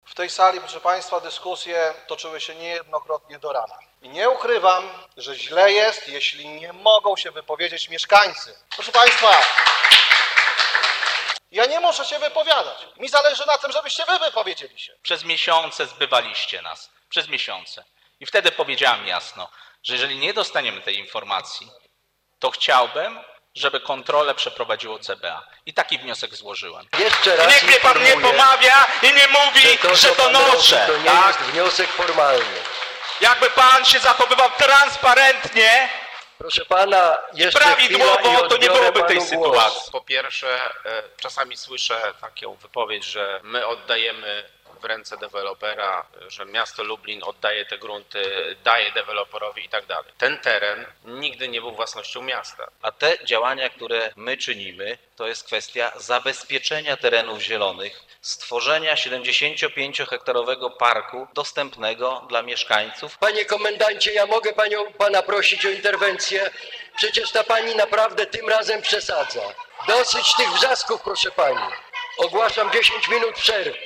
Dyskusja przed głosowaniem była bardzo gorąca, a na sali pojawiła się grupa protestujących mieszkańców:
Dyskusja-byla-bardzo-goraca.mp3